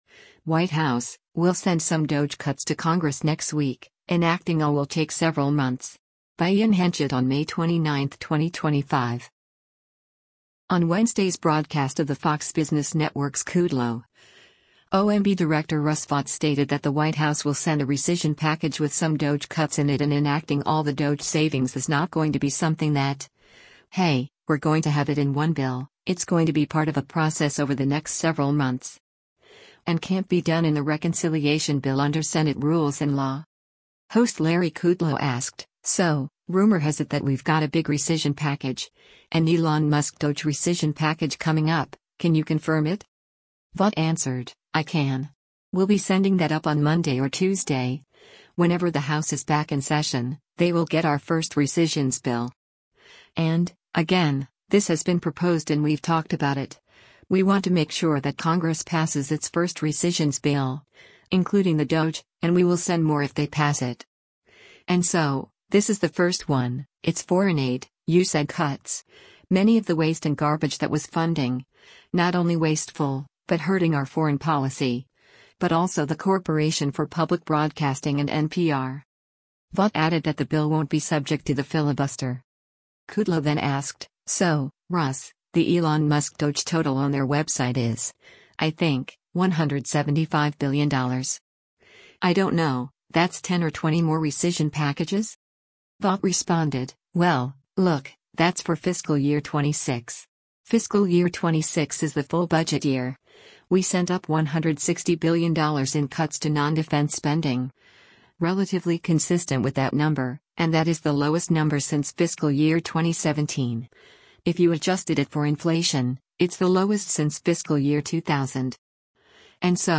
On Wednesday’s broadcast of the Fox Business Network’s “Kudlow,” OMB Director Russ Vought stated that the White House will send a rescission package with some DOGE cuts in it and enacting all the DOGE savings is “not going to be something that, hey, we’re going to have it in one bill, it’s going to be part of a process over the next several months.”